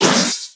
stunned_3.ogg